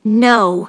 synthetic-wakewords
ovos-tts-plugin-deepponies_Starlight_en.wav